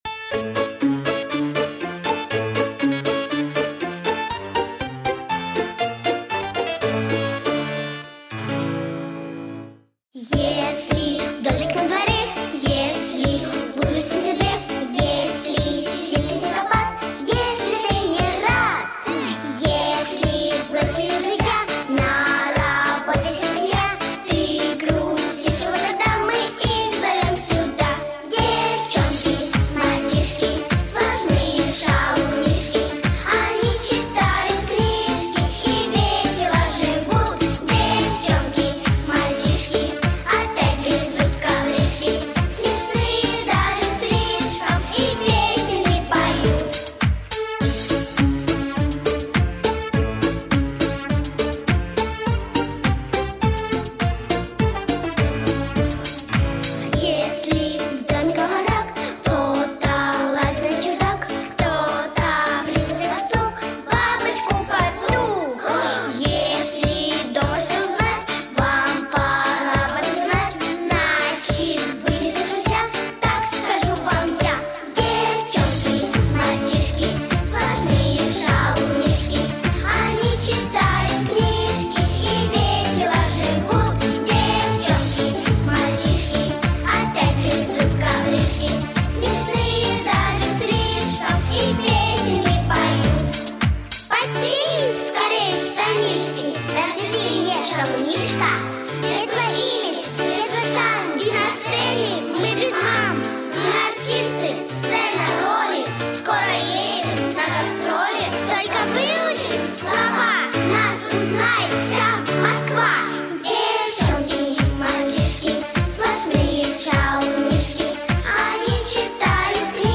Детская песенка